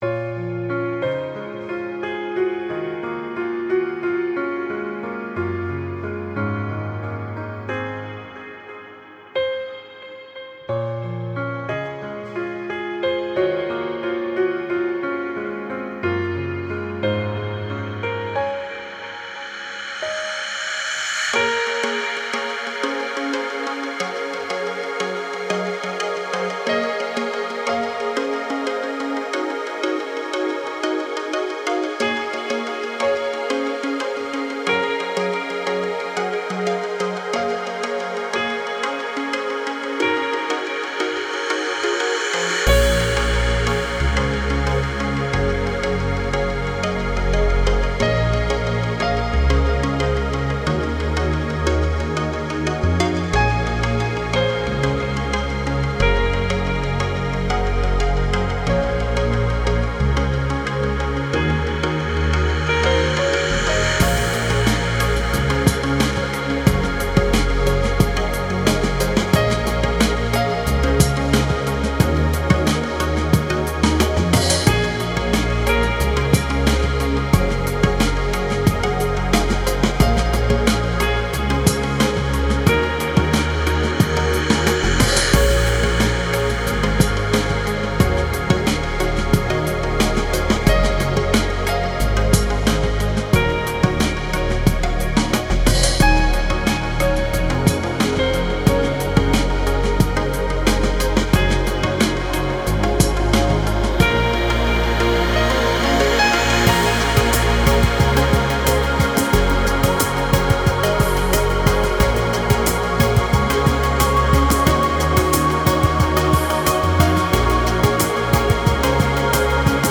Стиль: Chillout/Lounge / Ambient/Downtempo